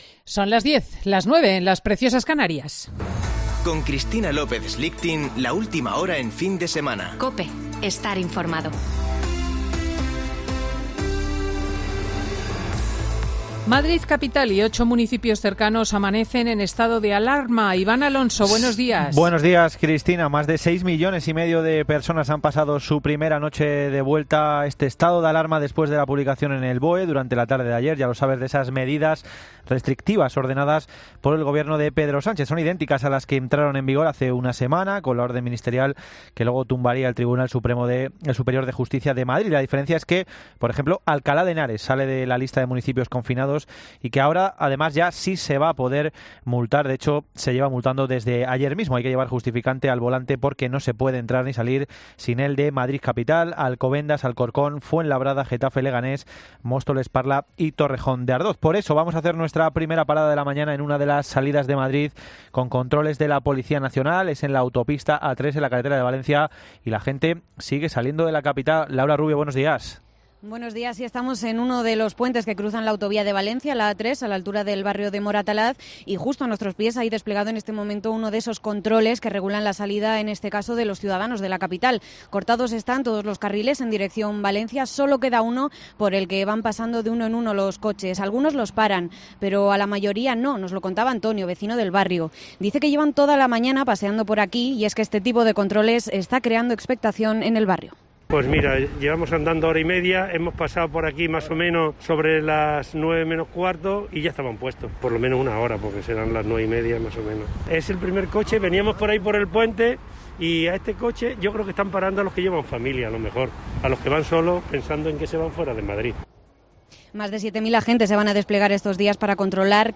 Boletín de noticias COPE del 10 de octubre de 2020 a las 10.00 horas